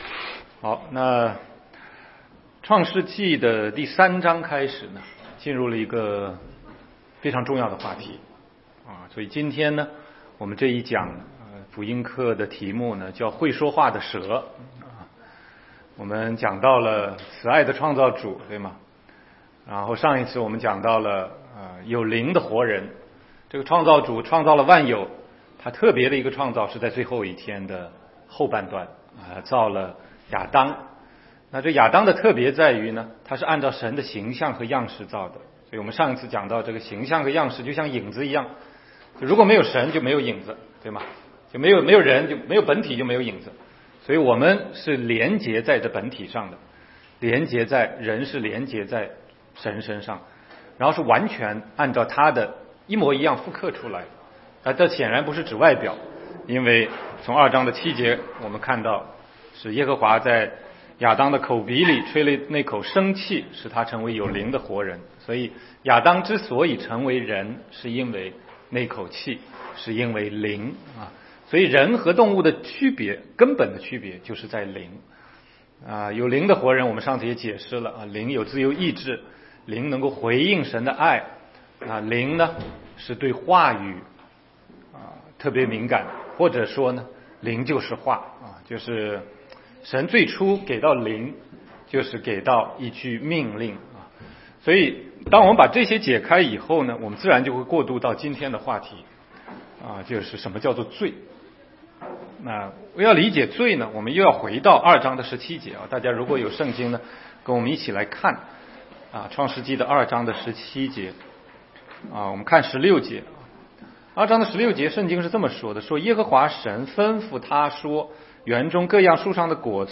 16街讲道录音 - 会说话的蛇